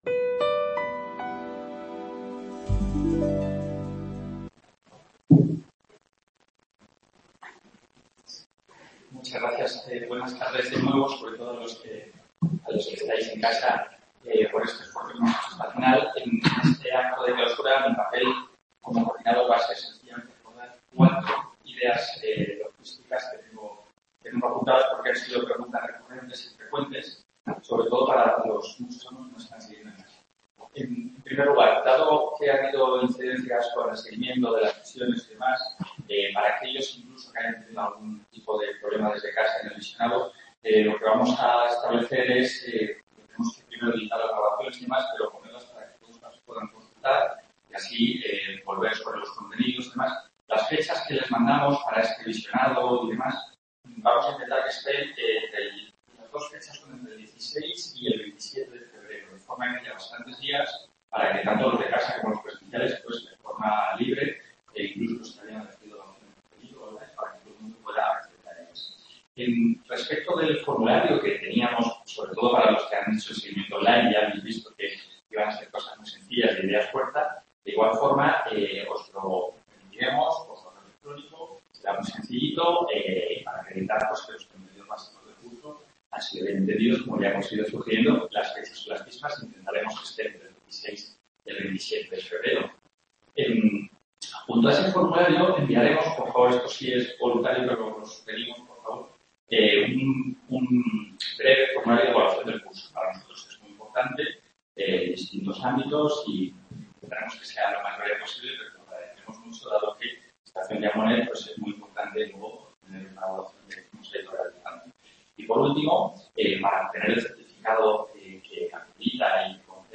Ponencia Clausura